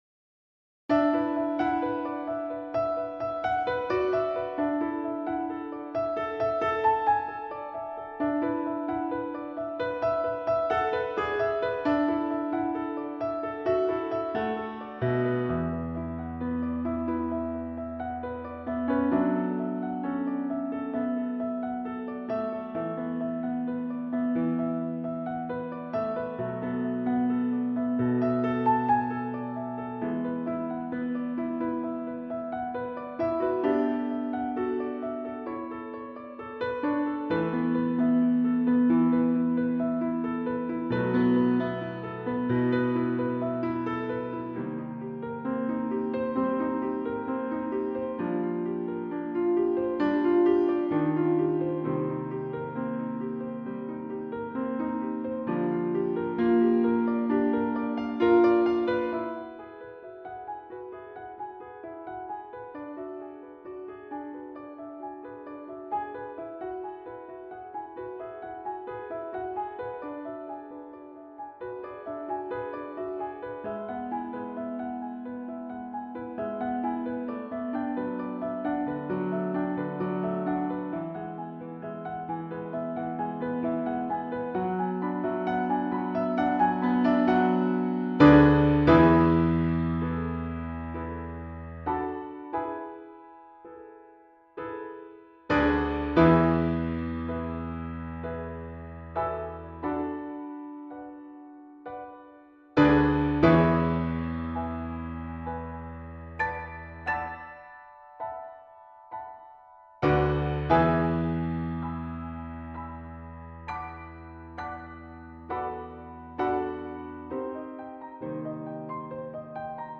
Sunstreams Piano backing